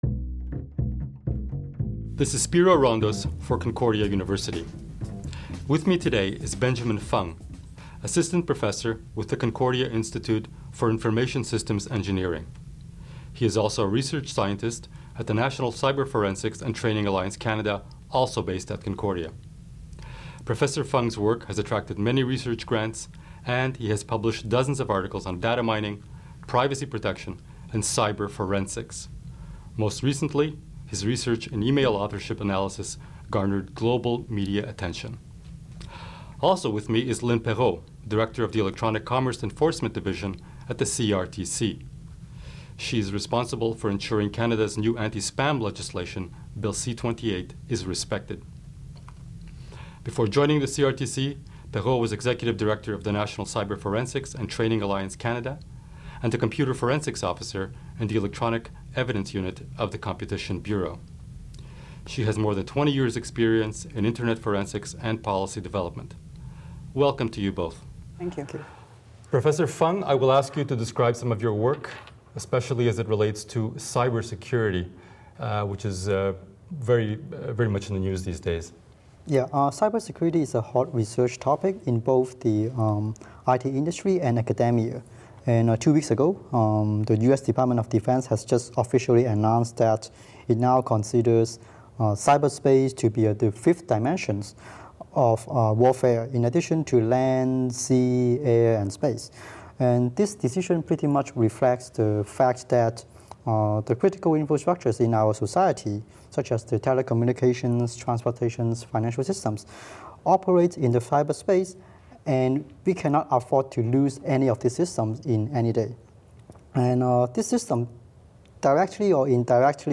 We have teamed them up with a community thought-leader to discuss a common topic, creating conversations intended to generate broader discussion and reflection.